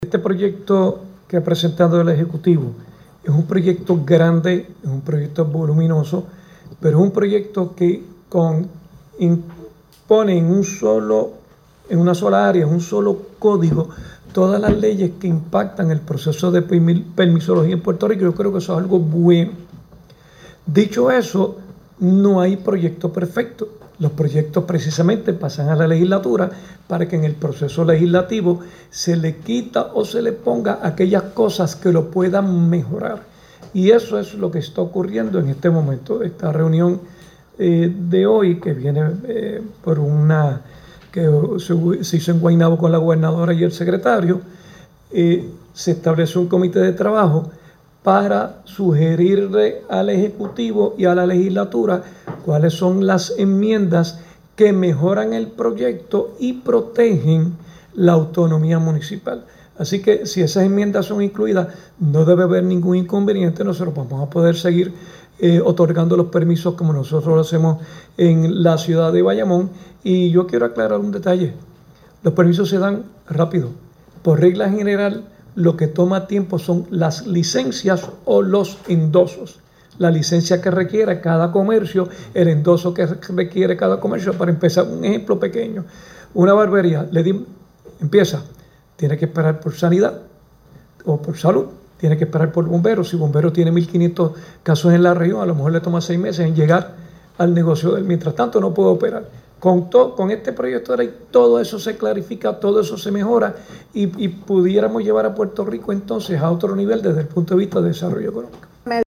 “Si las enmiendas recomendadas son incluidas, no debe haber ningún inconveniente” dice el alcalde de Bayamón sobre el Proyecto de Reforma del Sistema de Permisos (sonido)